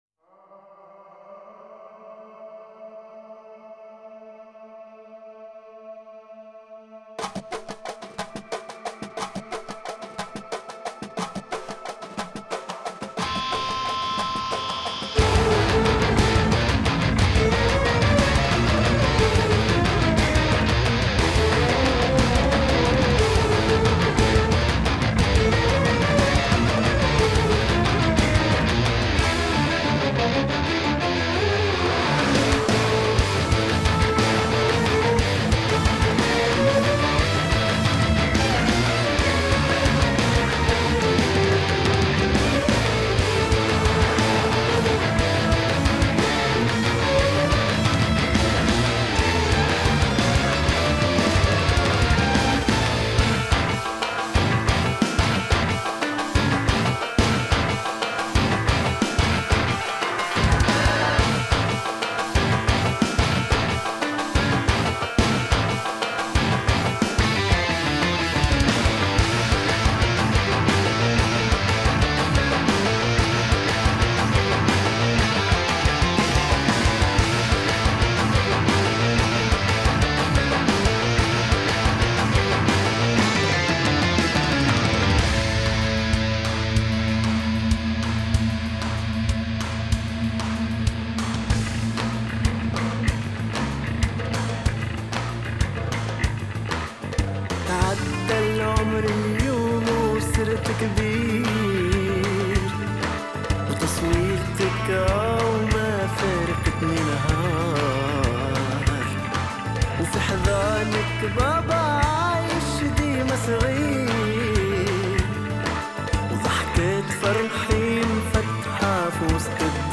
Progressive metal